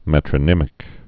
(mētrə-nĭmĭk, mĕtrə-)